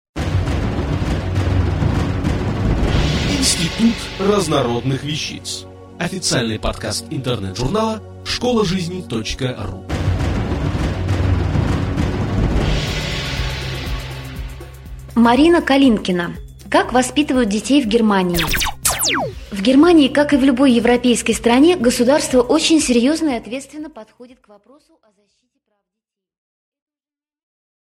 Aудиокнига Как воспитывают детей в Германии?